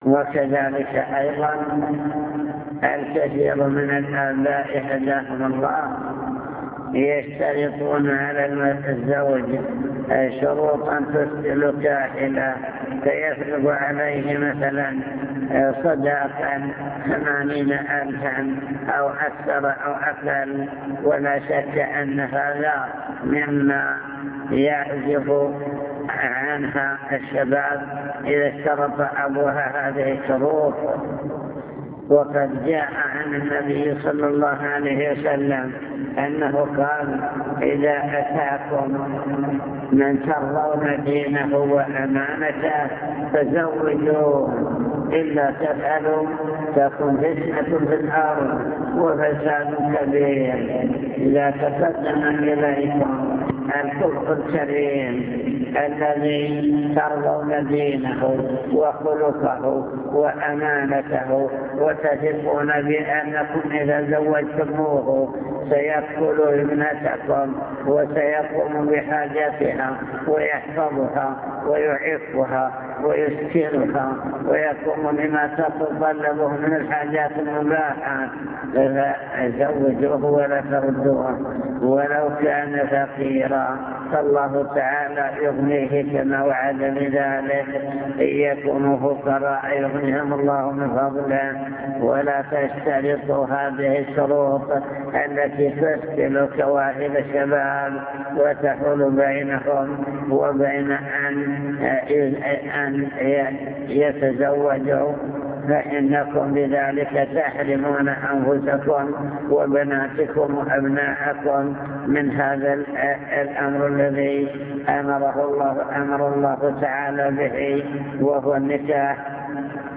المكتبة الصوتية  تسجيلات - محاضرات ودروس  محاضرات في الزواج